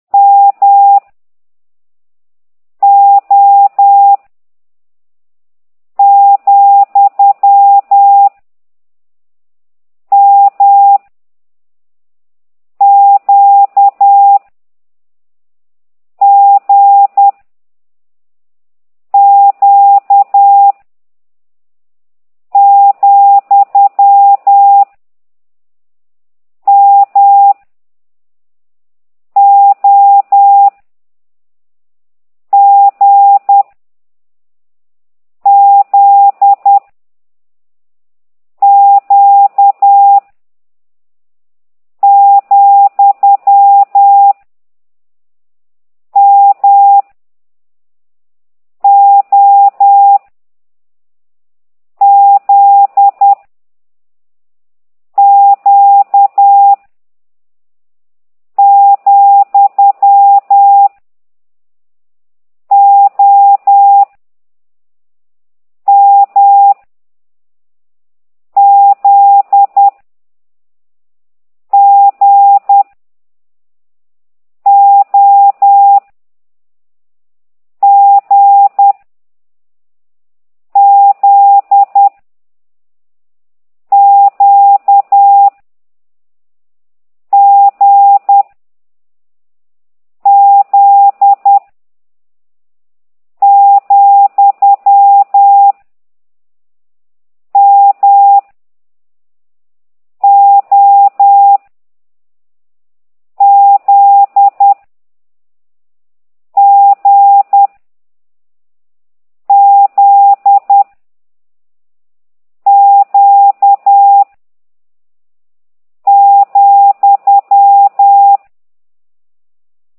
M dah dah O dah dah dah G dah dah di Z dah dah di dit Q dah dah di dah , dah dah di di dah dah